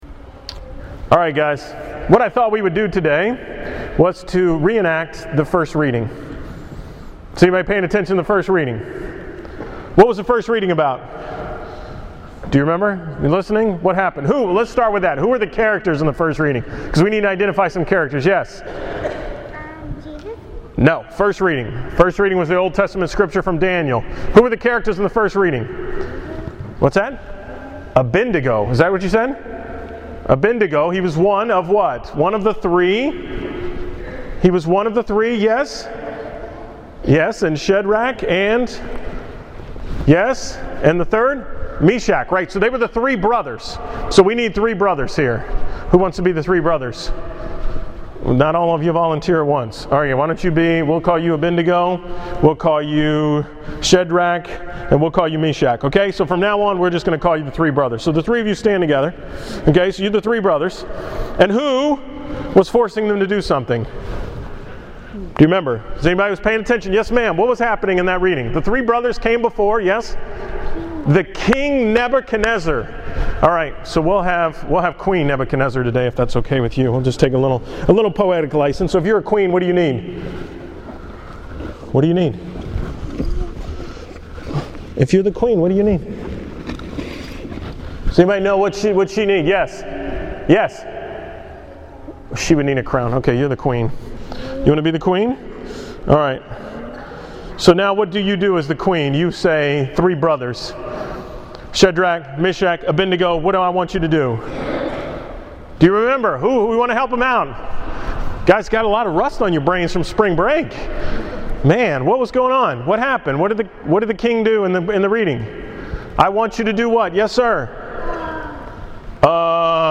The story of Shadrach, Meshach, and Abednego for the School Mass